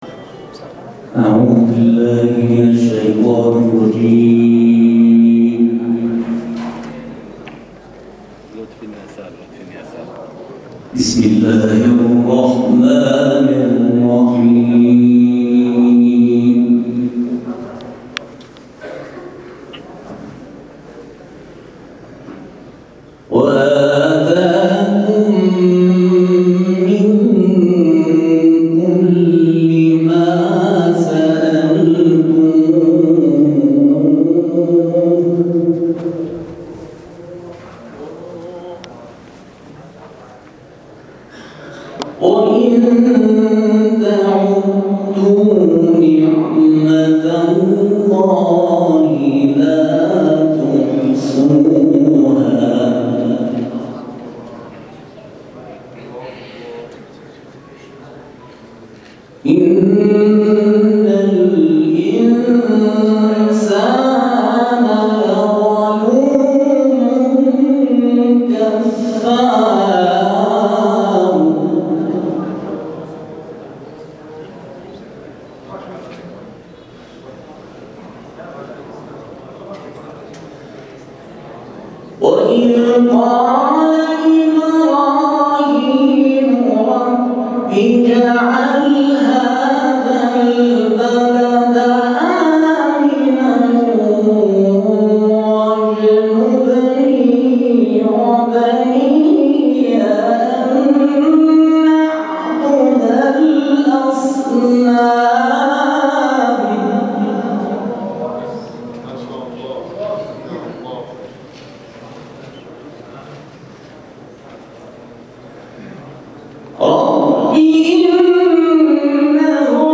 تلاوت نماینده کشورمان در مرحله مقدماتی مسابقات بین‌المللی طلاب + دانلود